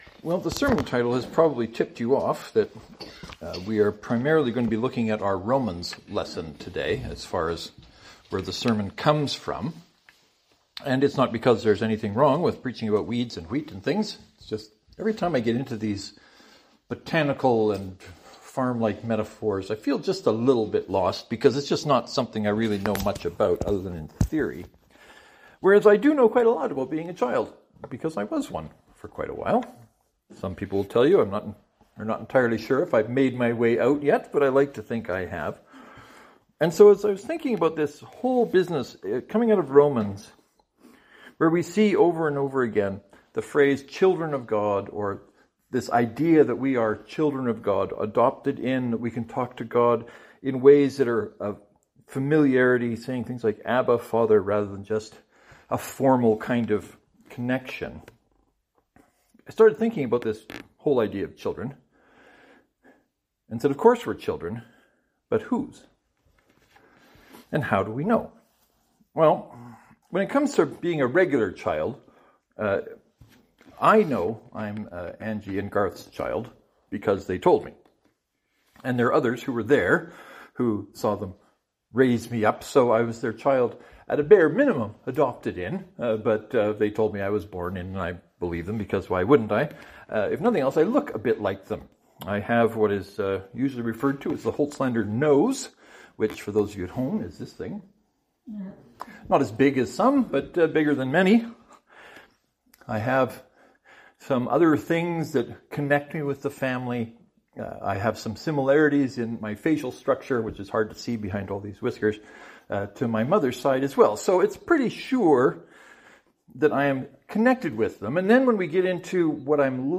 Today’s sermon is considering what it means to be a child of God and what kinds of resemblances there might be between divine parent and human child.
St. Mark’s Presbyterian (to download, right-click and select “Save Link As .